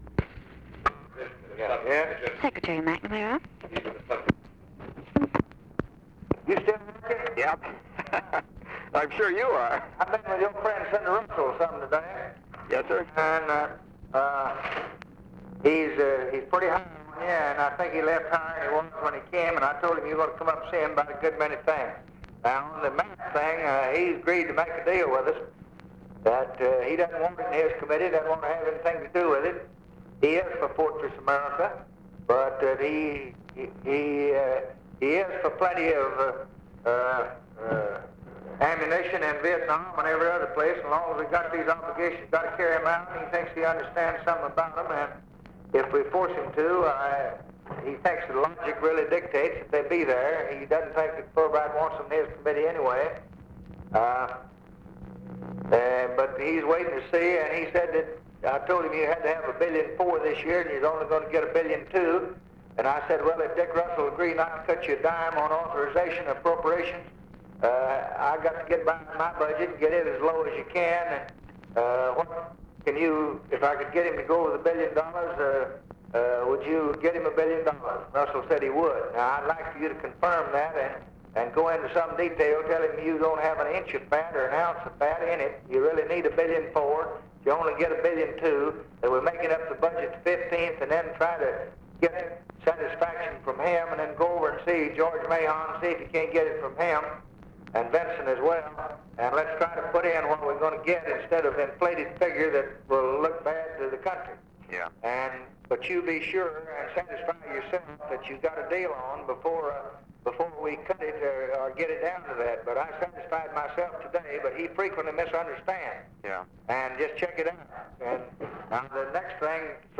Conversation with ROBERT MCNAMARA, December 7, 1963
Secret White House Tapes